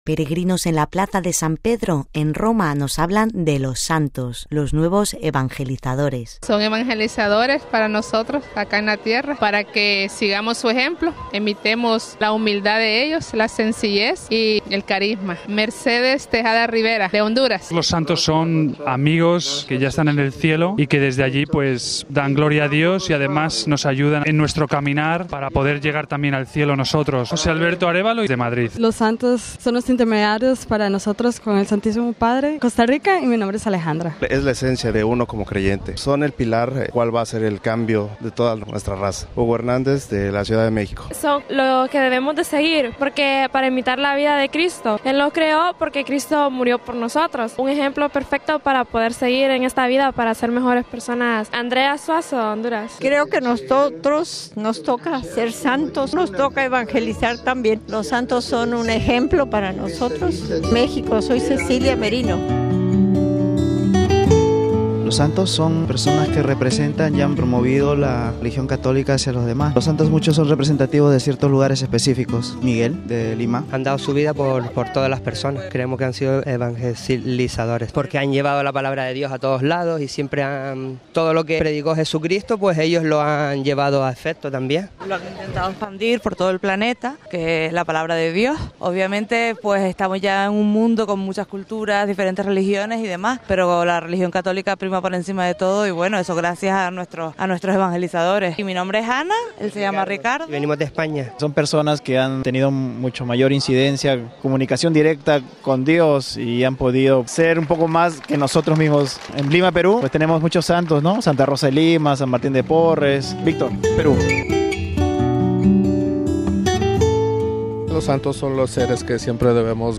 (RV).-En la Solemnidad de todos los Santos recorrimos la Plaza de San Pedro, en donde este mediodia se habían reunido miles de fieles para escuchar las palabras del Papa y rezar con él, y entrevistamos a algunos de los iberoamericanos presentes que nos hablaron de los santos y de su figura de ‘actuales evangelizadores’, como los definió Benedicto XVI.